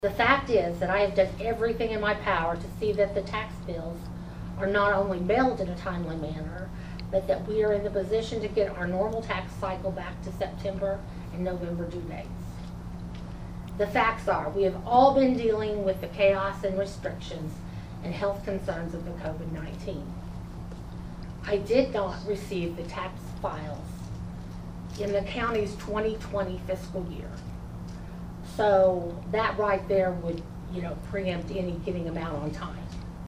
Over the next hour, only one individual abided by that request and it was mostly a group conversation that resembled Great Britain’s parliamentary procedures with interruptions and occasional raised voices.